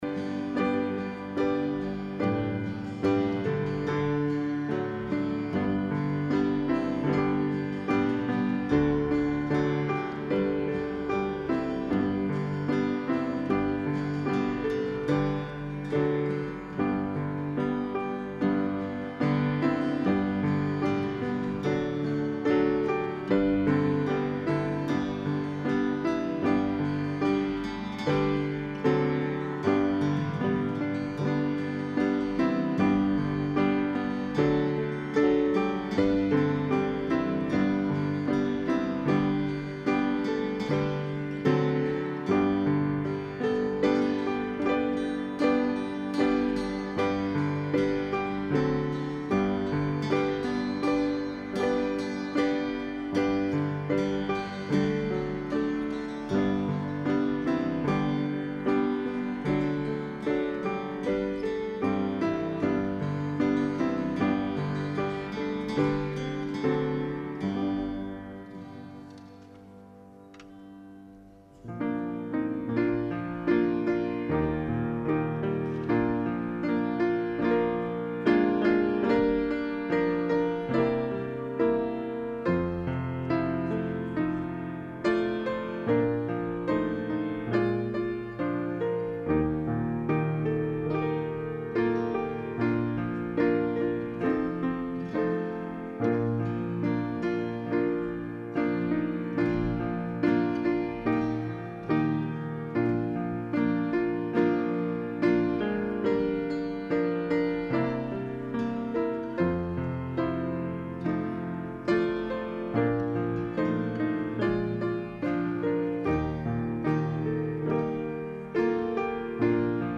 01-28-26pm-Deacon Ordination Service